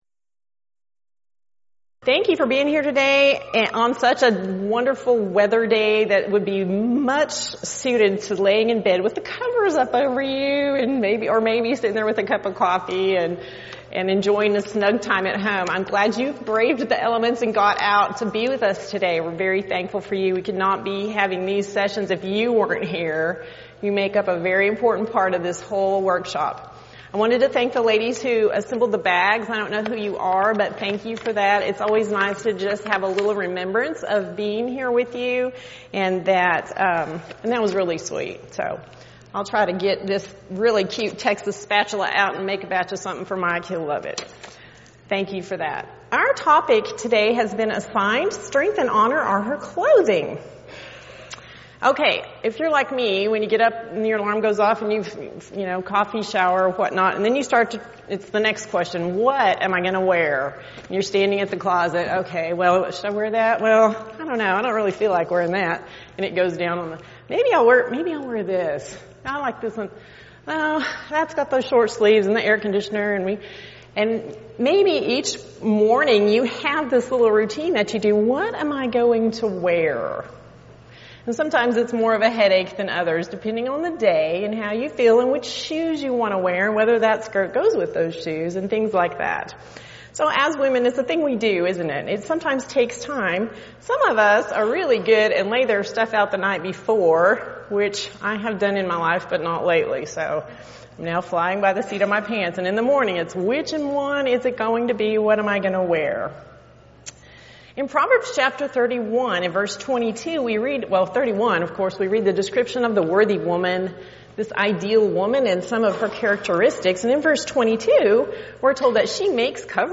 Preacher's Workshop
lecture